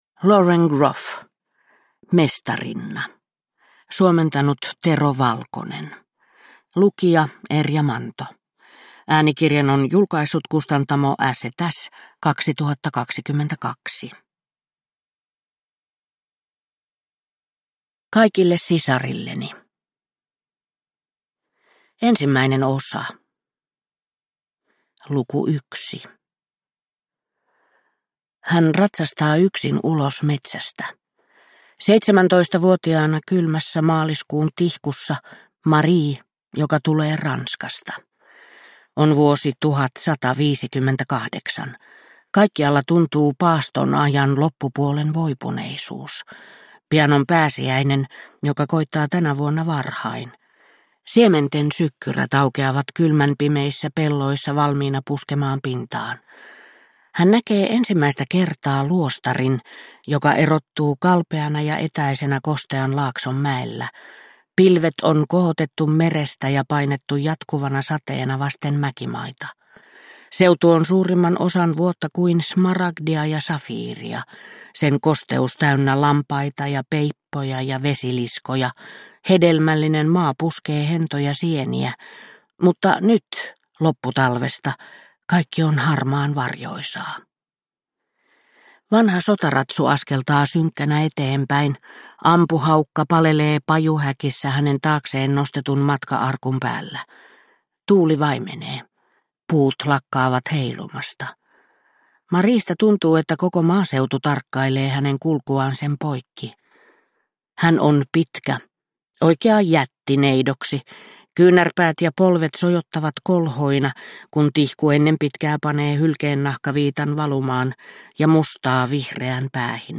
Mestarinna – Ljudbok – Laddas ner